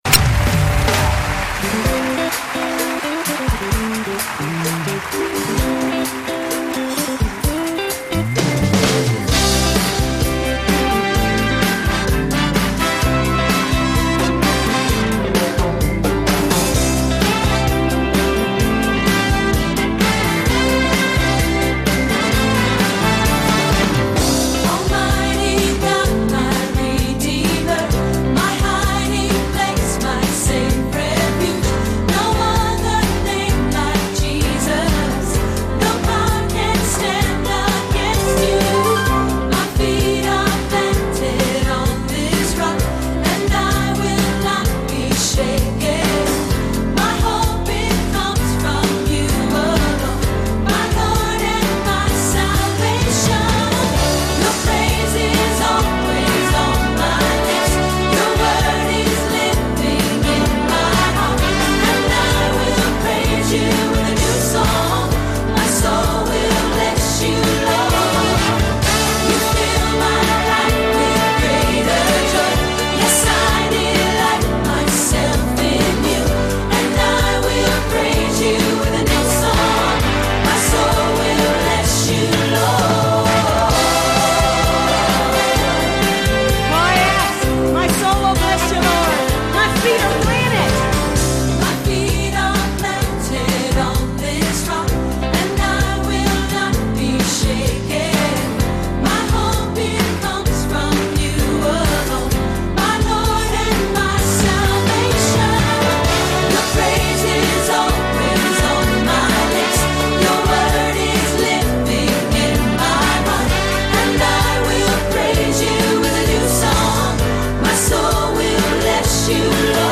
Experience this monumental gospel worship anthem today.